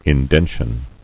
[in·den·tion]